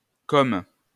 wymowa:
IPA[kɔm] ?/i